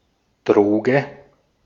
Ääntäminen
IPA: [ˈdʁoːɡə]